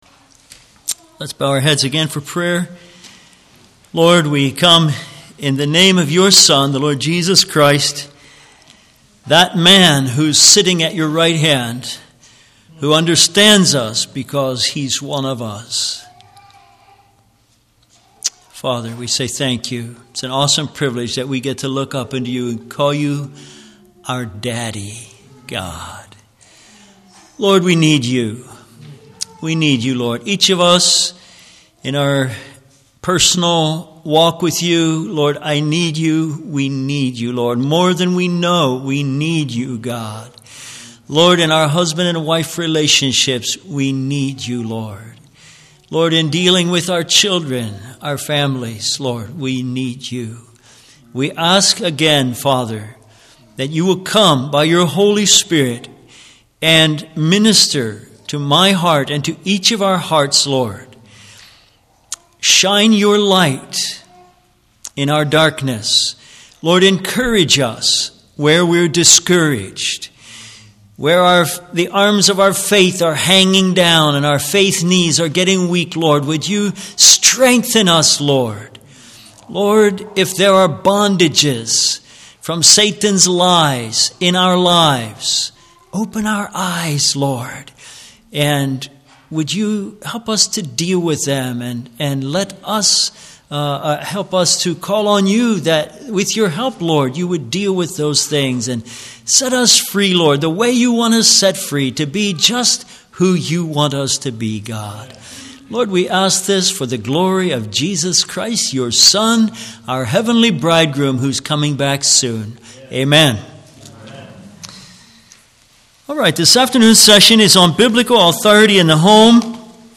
Service Type: Ladies Seminar, Men's Seminar